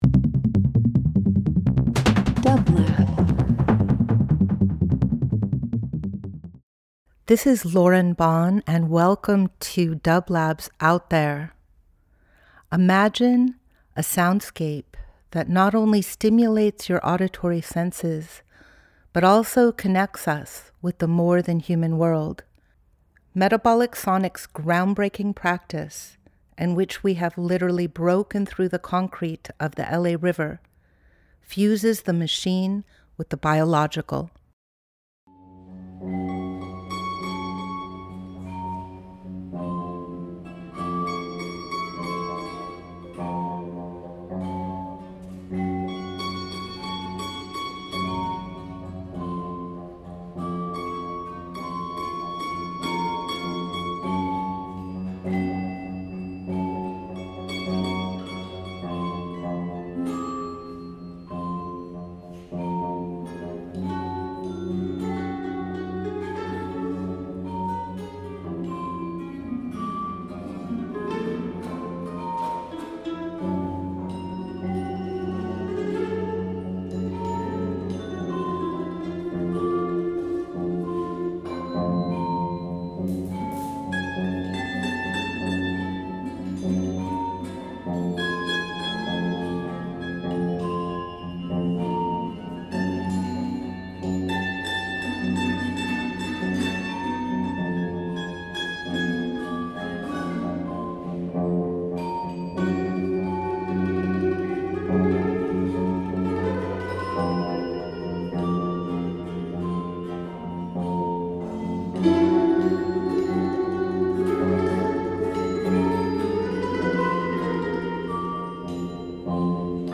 Out There ~ a field recording program
Each week we present field recordings that will transport you through the power of sound. Metabolic Sonics specializes in exploring the captivating sound waves and vibrations of the web of life.
Each week we will bring field recordings of Metabolic’s current project Bending the River, and archival material from past ventures. Bending the River is an adaptive reuse of the LA River infrastructure that reimagines the relationship between Los Angeles and the river that brought it into existence.